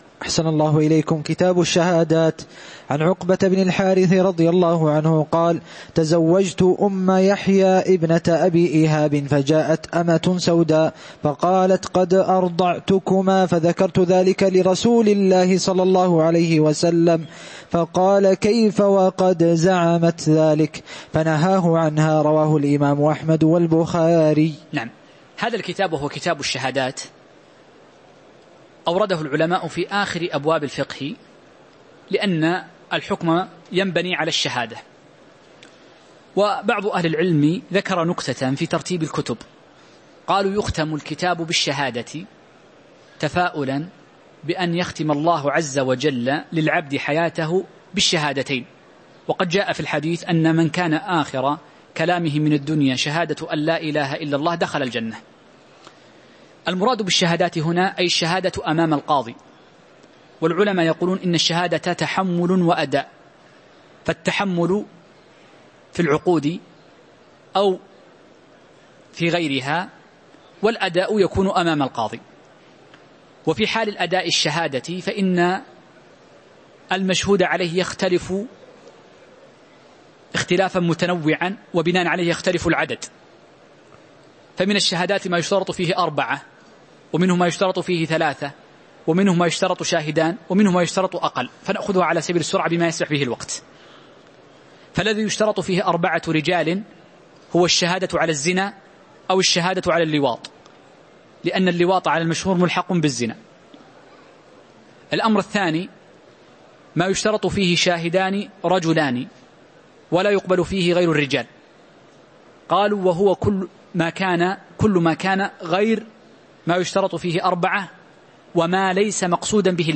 تاريخ النشر ١٢ ربيع الأول ١٤٤١ هـ المكان: المسجد النبوي الشيخ